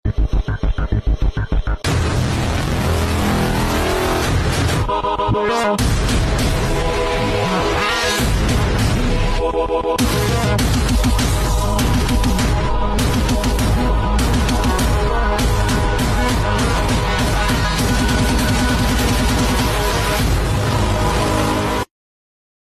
😱 KCM Modified Clipper/Trimmer combo sound effects free download